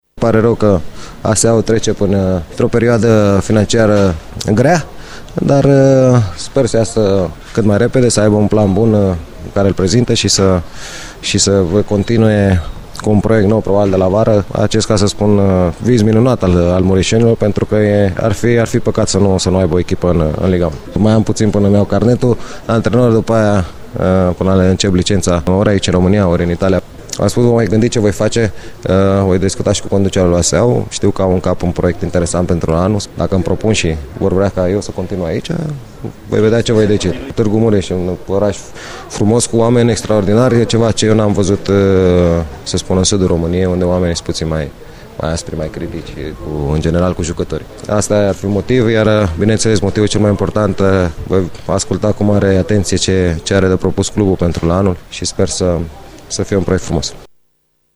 Jucătorul echipei ASA Tg.Mureș, Adrian Mutu, a declarat la finalul partidei cu FC Viitorul, încheiată la egalitate, scor 1-1, că nu este exclus ca să rămână și sezonul viitor la gruparea „roș-albastră”.